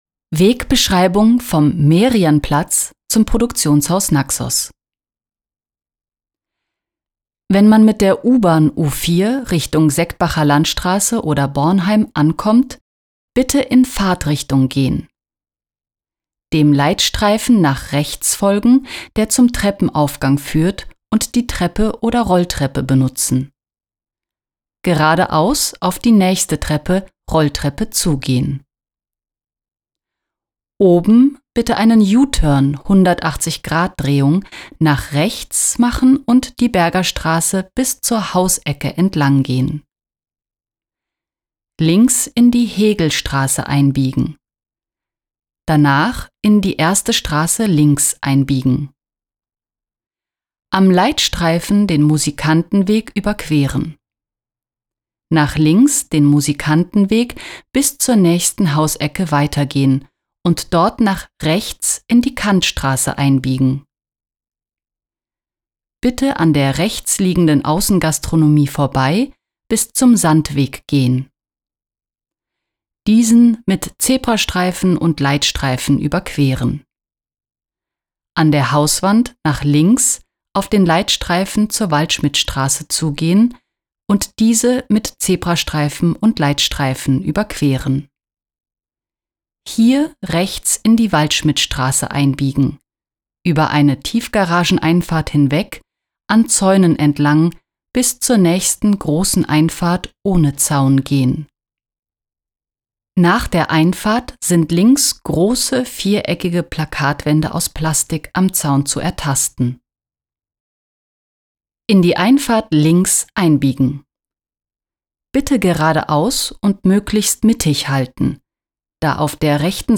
Wegbeschreibungen, insbesondere für blinde und sehbehinderte Personen
naxos-wegbeschreibung-merianplatz1.mp3